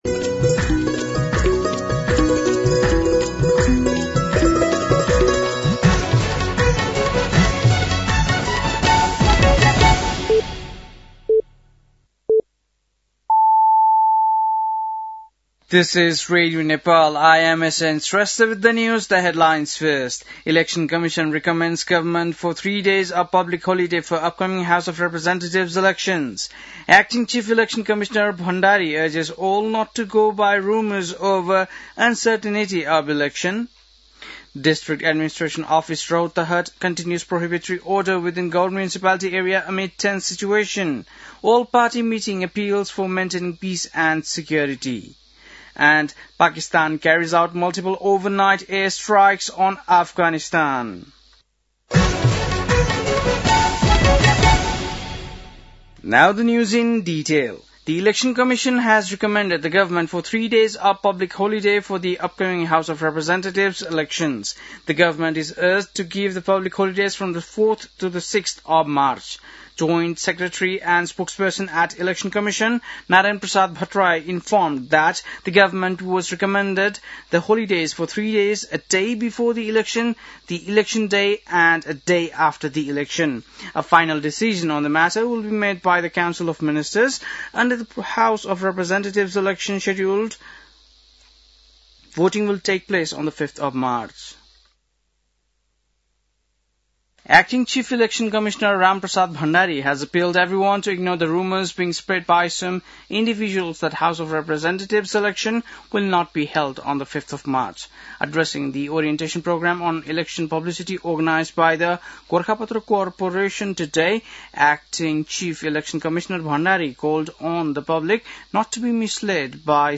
बेलुकी ८ बजेको अङ्ग्रेजी समाचार : १० फागुन , २०८२
8-pm-english-news-11-10.mp3